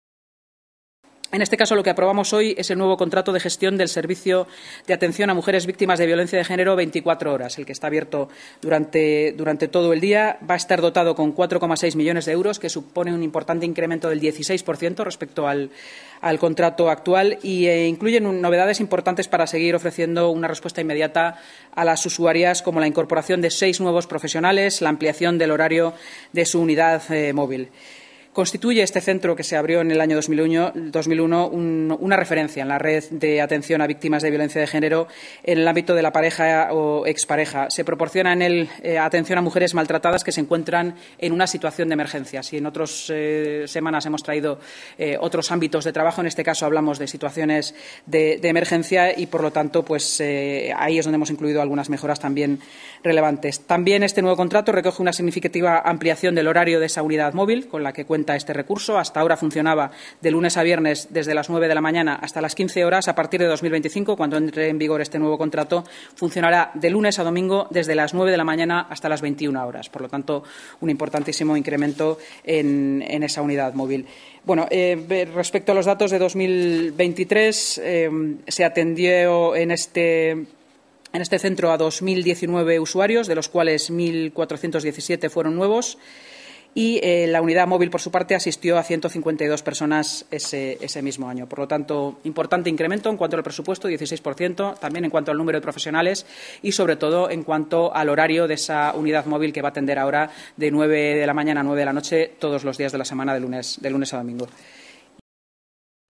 Nueva ventana:Intervención de la vicealcaldesa y delegada de Portavoz, Seguridad y Emergencias, Inma Sanz, en la rueda de prensa posterior a la Junta de Gobierno